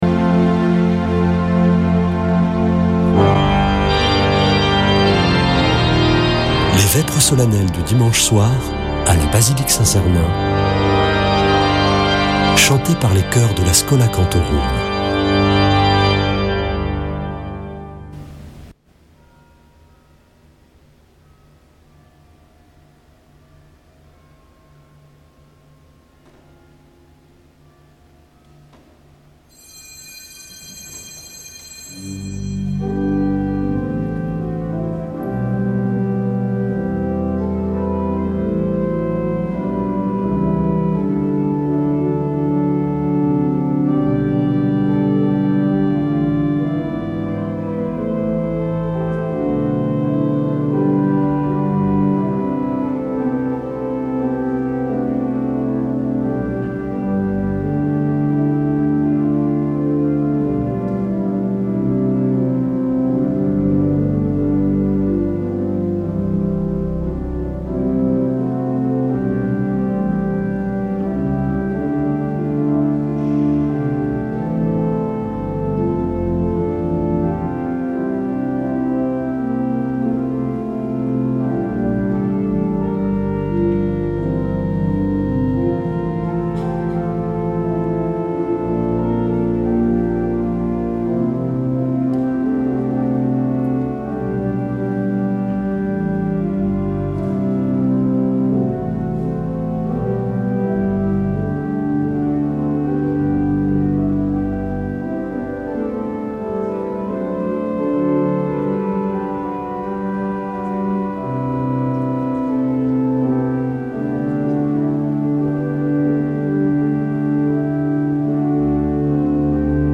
Vêpres de Saint Sernin du 01 juin